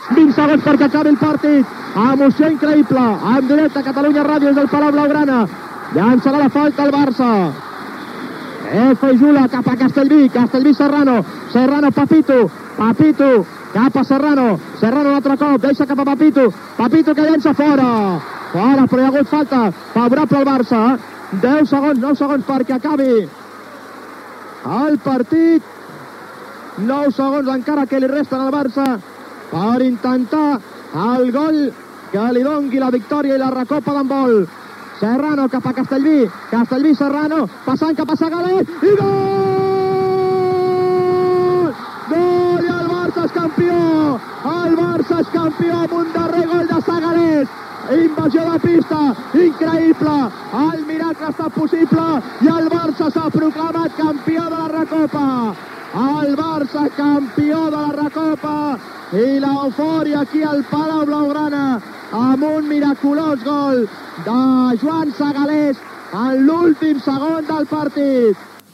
Final de la retransmissió del partit de tornada de la final de la Recopa d'Europa d'handbol. El F.C. Barcelona guanya al CSKA de Moscou, al Palau Blaugrana i aconsegueix la segona Recopa d'Europa d'handbol.
Esportiu